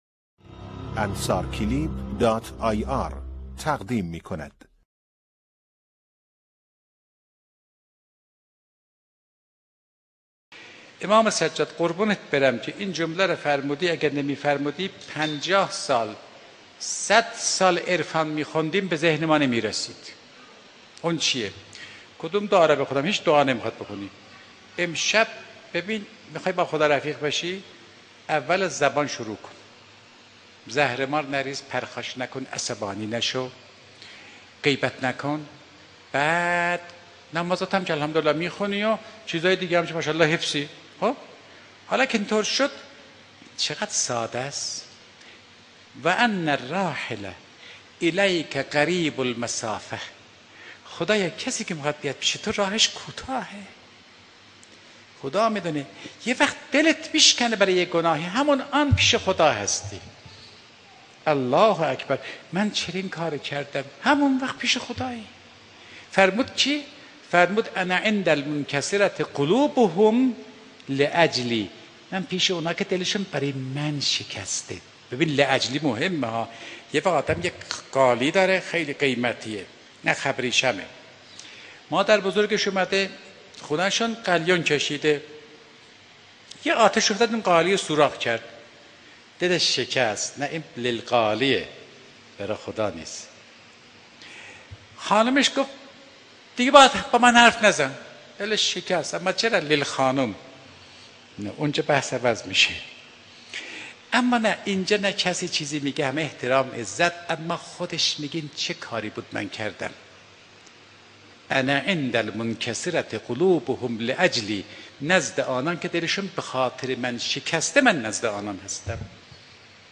سخنرانی | پیامی از سوی خداوند به بندگانش
فرازی از سخنان حجت الاسلام سید عبدالله فاطمی نیا در جلسه‌ی ششم سیری در صحیفه سجادیه | سی و یکم خردادماه 1394